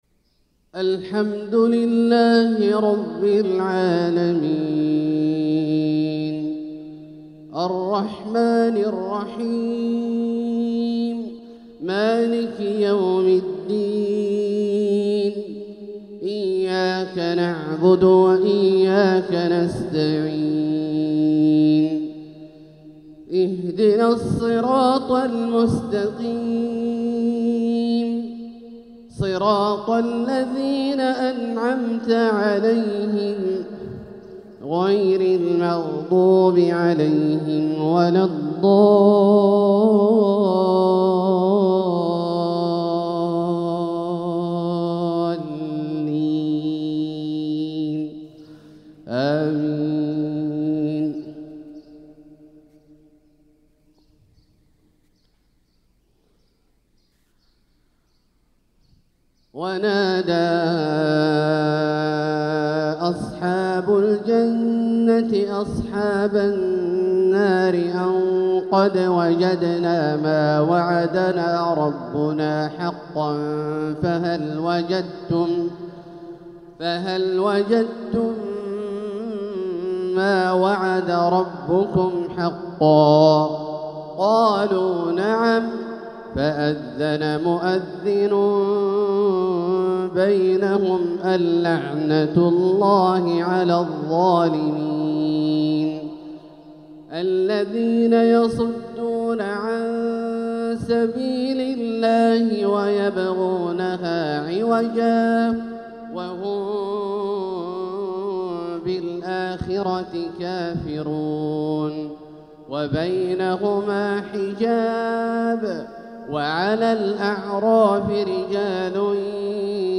تلاوة من سورة الأعراف | فجر الثلاثاء 7-7-1446هـ > ١٤٤٦ هـ > الفروض - تلاوات عبدالله الجهني